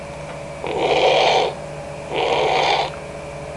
Blow Nose Sound Effect
Download a high-quality blow nose sound effect.
blow-nose.mp3